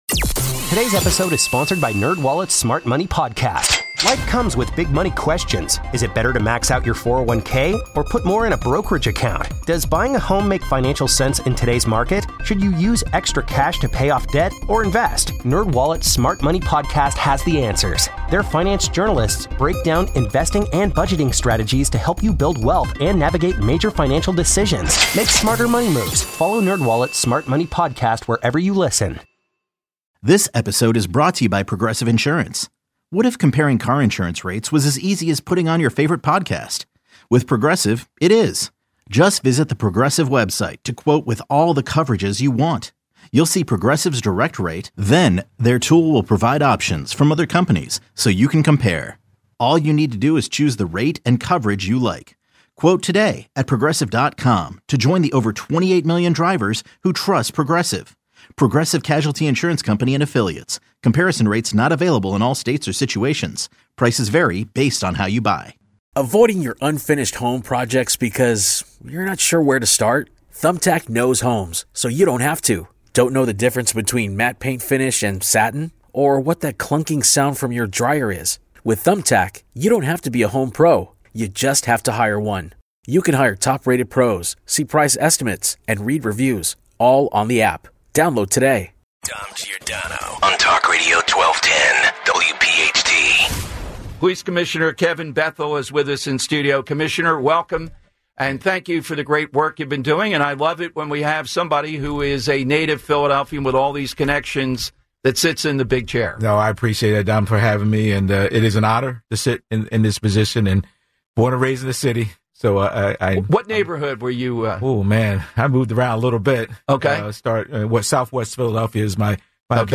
Philadelphia Police Commissioner Kevin Bethel Live in Studio